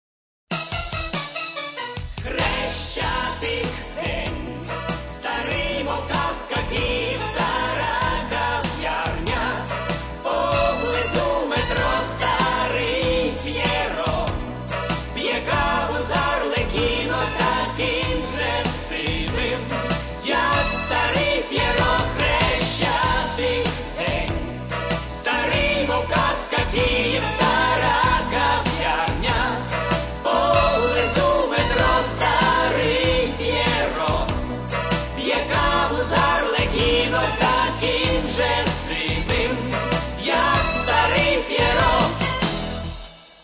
Здається, цим пісням дещо бракує тихого шурхотіння.